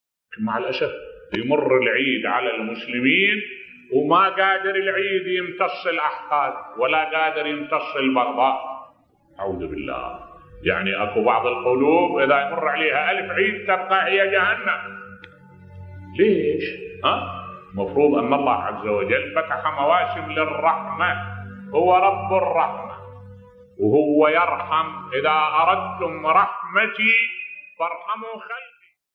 ملف صوتی بعض القلوب لو يمر عليها ألف عيد تبقى هي جهنم بصوت الشيخ الدكتور أحمد الوائلي